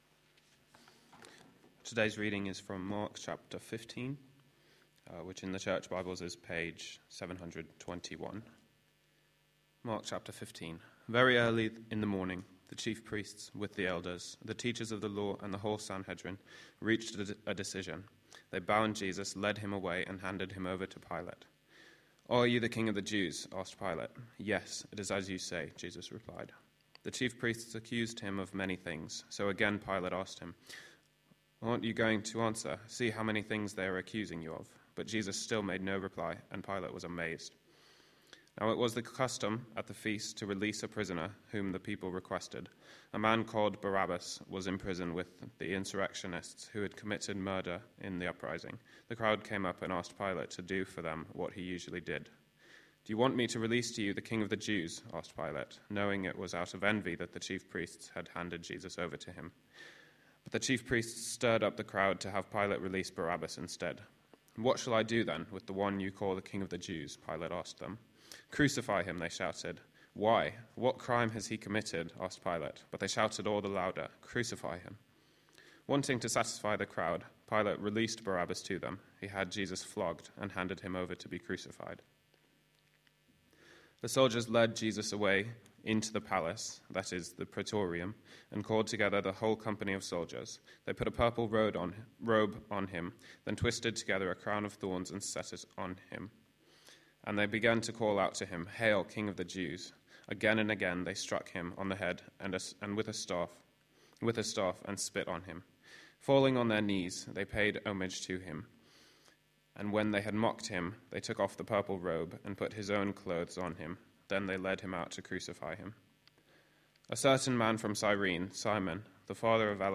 A sermon preached on 1st April, 2012, as part of our Mark series.